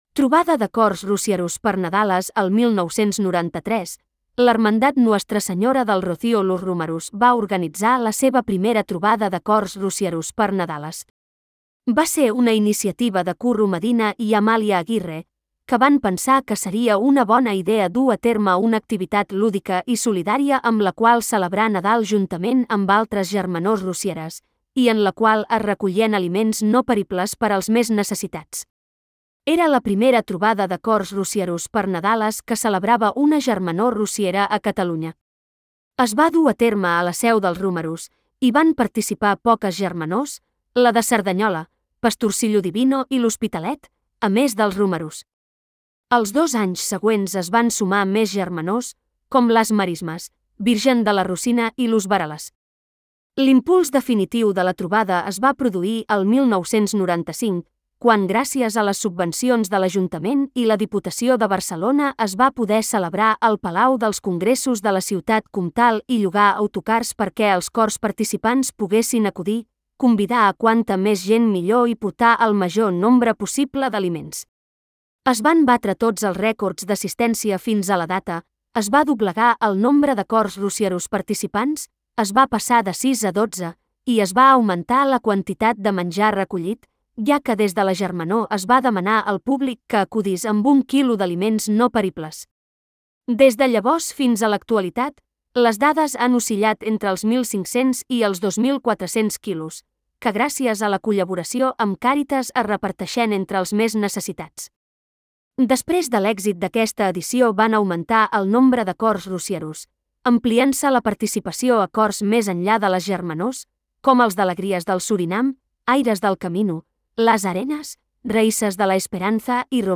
Trobada de Cors Rocieros per Nadales. 2014.
Trobada-de-Cors-Roci.m4a